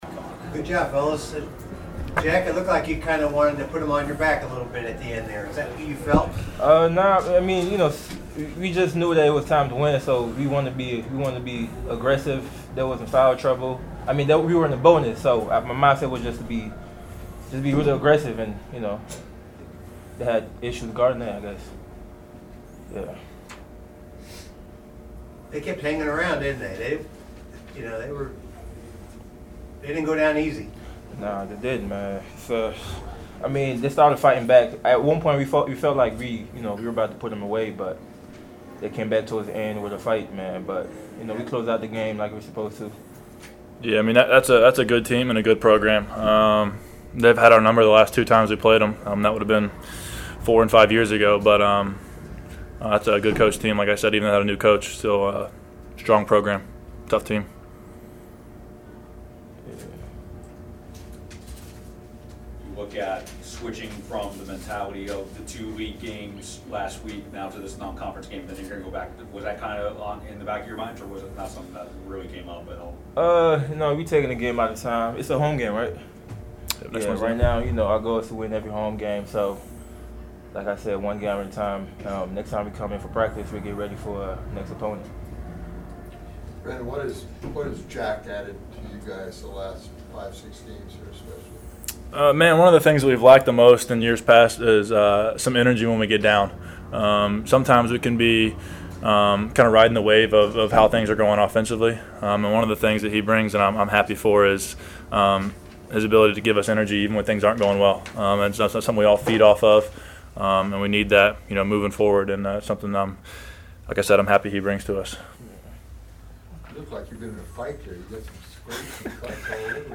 Full Postgame Press Conference